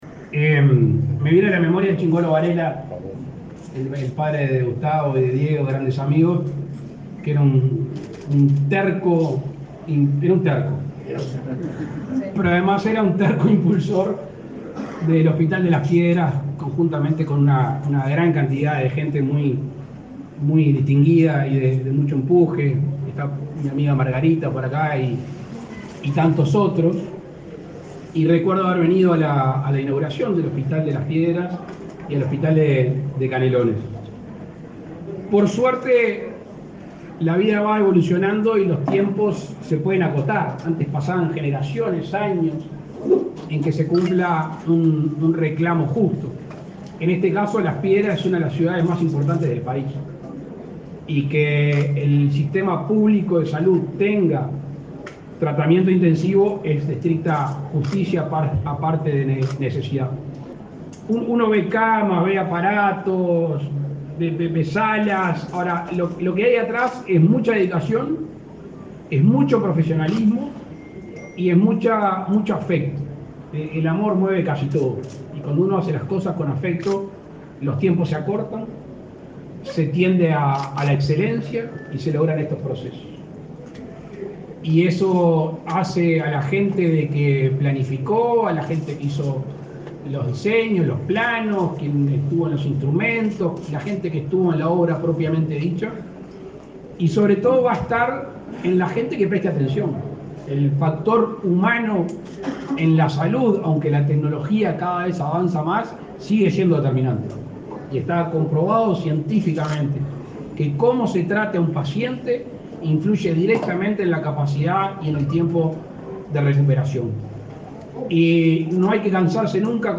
Palabras del presidente Lacalle Pou en inauguración de CTI de Hospital de Las Piedras
El mandatario hizo uso de la palabra en el acto de puesta en funcionamiento del nuevo servicio, el primero de este tipo, público, en el departamento